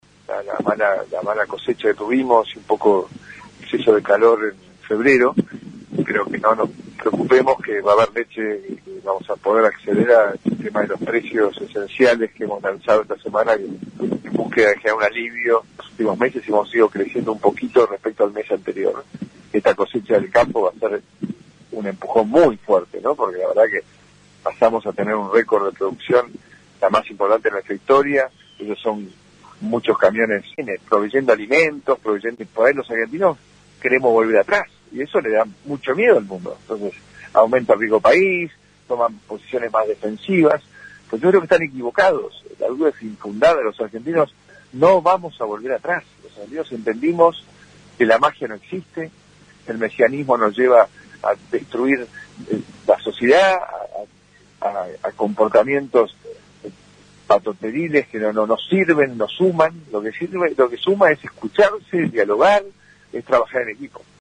En una entrevista con radio LT29 de Venado Tuerto, Santa Fe, luego de reunirse con productores locales en la localidad de Santa Emilia, en esa provincia, el Presidente sostuvo que «el discurso de que podíamos vivir con lo nuestro nos trajo un tercio de argentinos viviendo en la pobreza», pero consideró que la mentalidad de los argentinos «está empezando a cambiar».